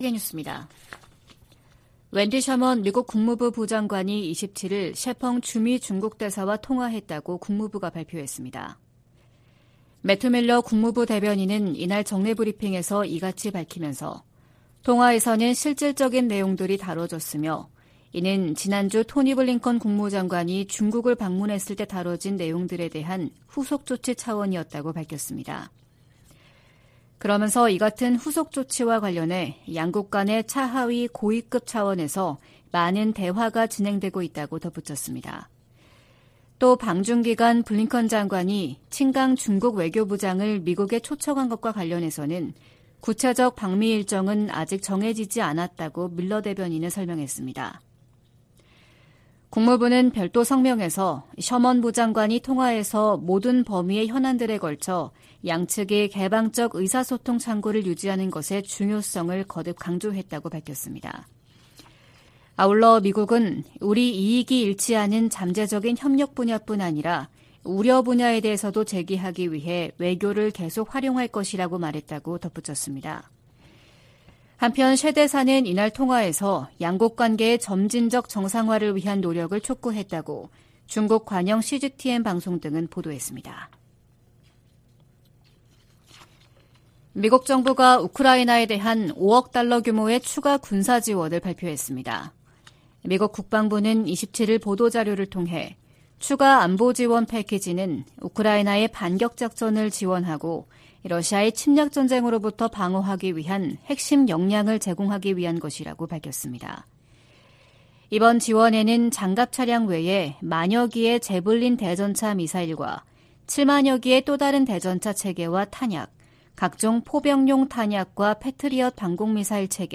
VOA 한국어 '출발 뉴스 쇼', 2023년 6월 29일 방송입니다. 미 국무부는 미국과 한국의 군사활동 증가와 공동 핵계획 탓에 한반도 긴장이 고조되고 있다는 중국과 러시아의 주장을 일축했습니다. 미 국방부는 북한의 핵무력 강화 정책 주장과 관련해 동맹국과 역내 파트너들과의 협력을 강조했습니다. 미 하원 세출위원회가 공개한 2024회계연도 정부 예산안은 북한과 관련해 대북 방송과 인권 증진 활동에만 예산을 배정하고 있습니다.